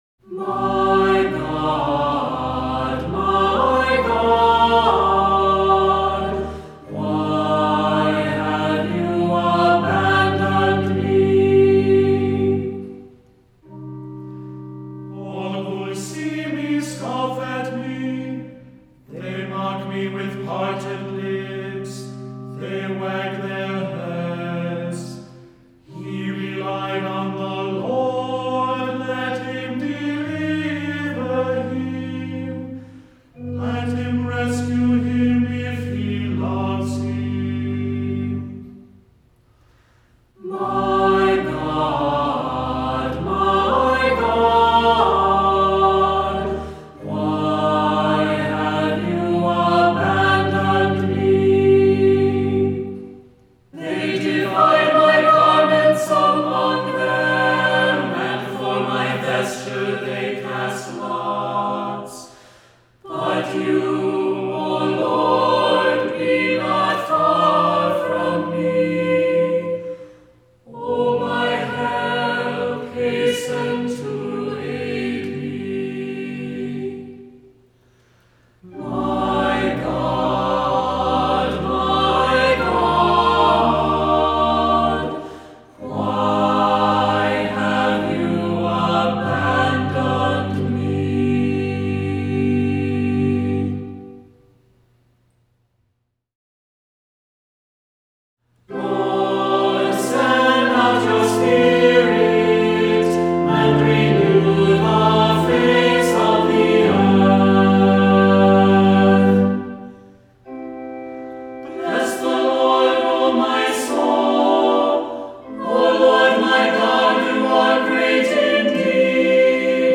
Voicing: Assembly,SATB, cantor